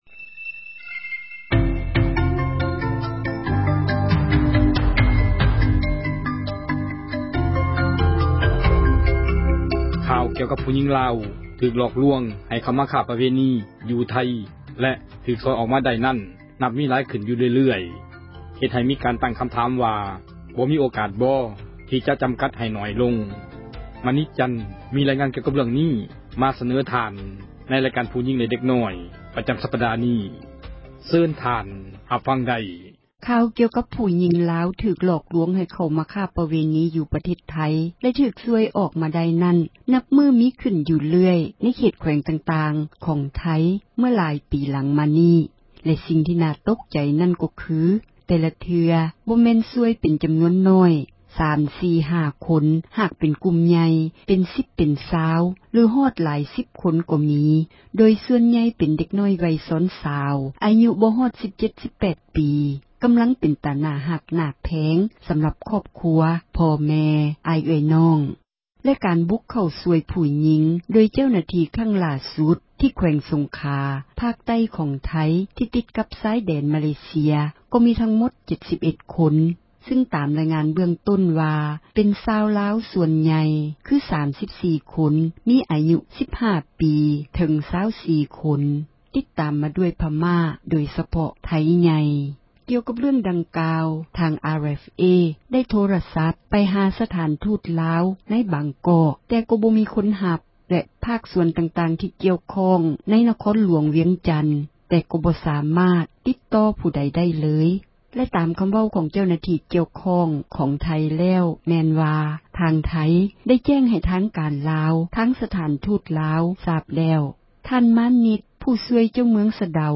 ເດັກນ້ອຍລາວຈະພົ້ນຈາກ ຖືກຫຼອກລວງໄດ້ແນວໃດ — ຂ່າວລາວ ວິທຍຸເອເຊັຽເສຣີ ພາສາລາວ